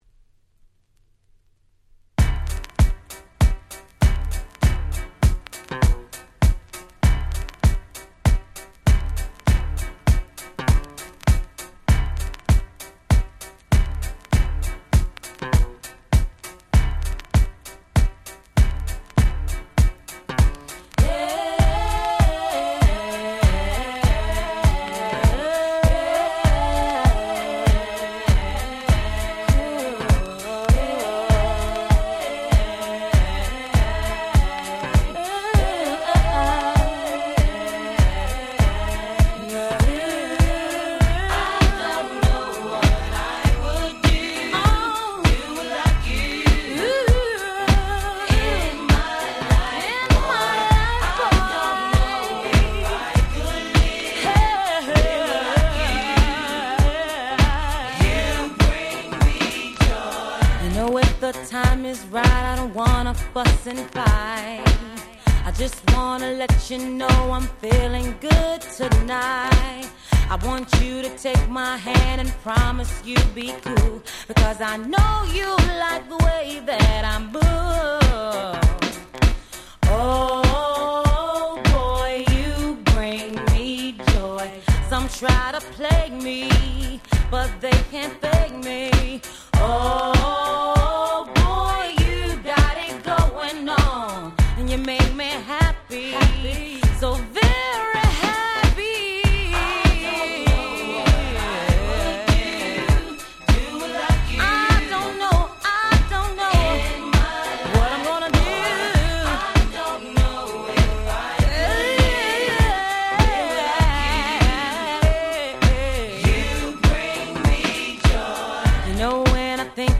95' Smash Hit R&B/Hip Hop Soul !!